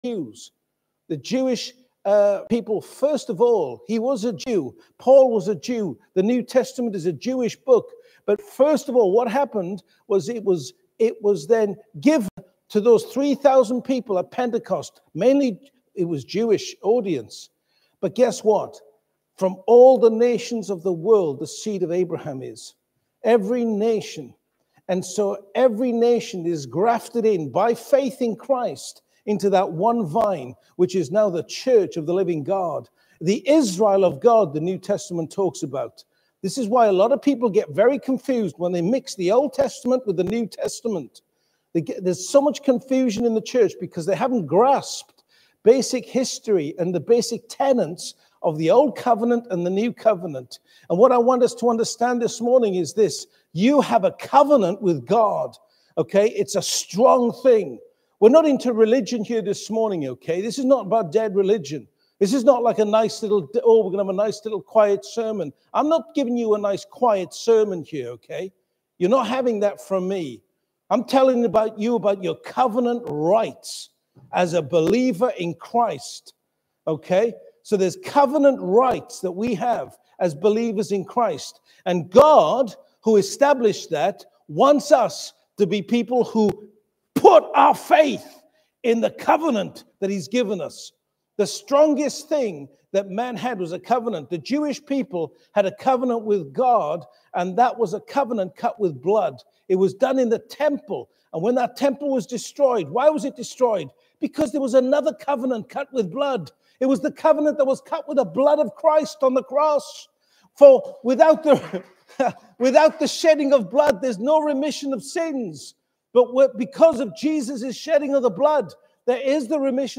Audio and video teachings from Living Faith Church